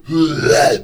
spawners_mobs_uruk_hai_attack.1.ogg